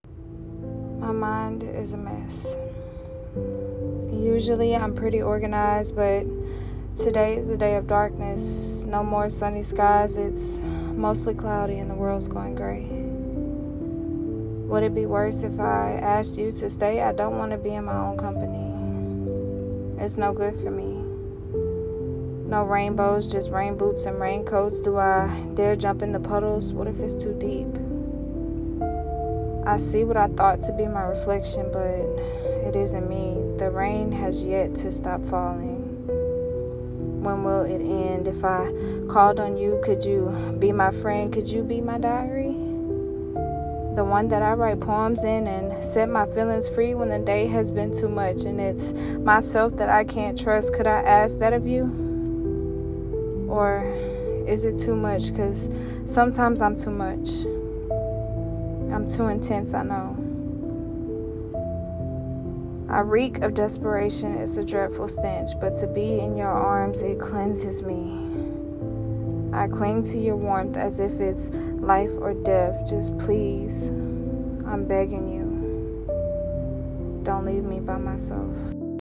Poetry
Your plea is so authentic and real, and your reading expresses the emotional element of the words themselves.